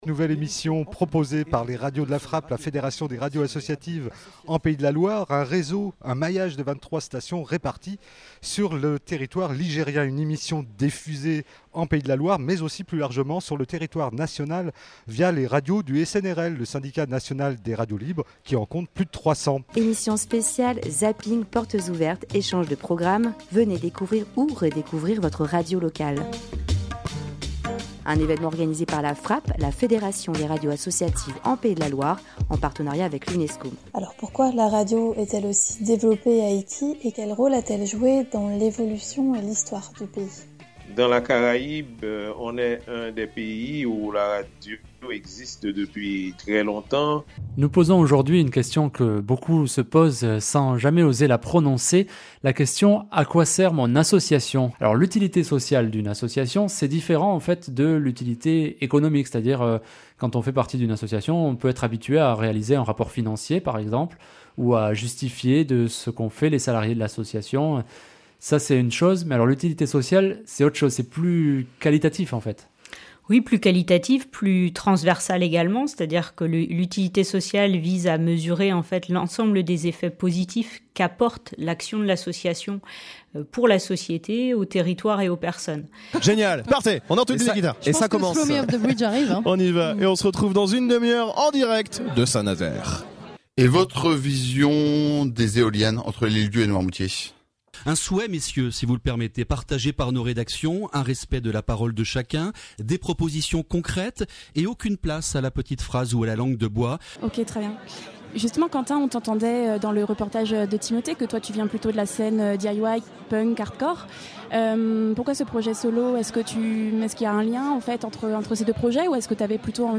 Vous étiez plus d’une soixantaine présents le vendredi 1er juillet dernier au Conseil Régional des Pays de la Loire pour les 10 ans de la fédération.
Les participants ont eu l’occasion d’intervenir sur les sujets débattus.